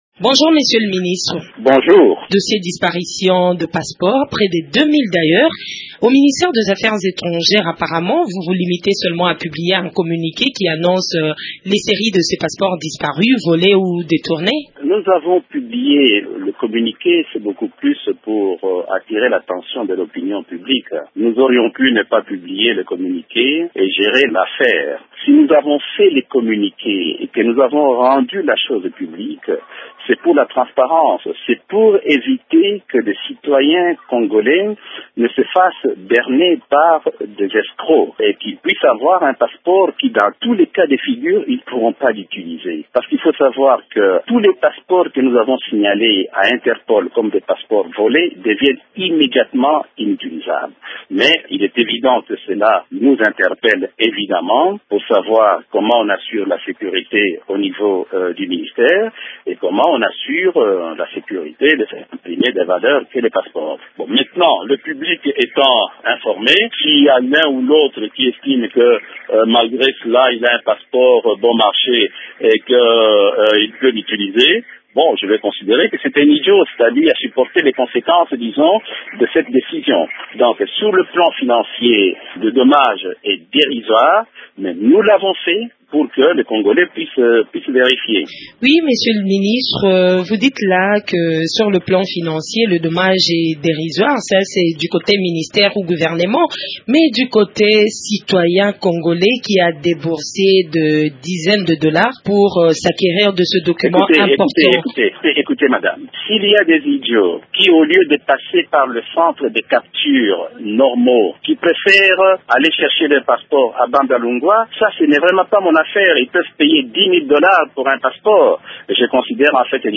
Tambwe Mwamba, ministre des Affaires étrangères
Le ministre des Affaires étrangères s’exprime sur cette affaire.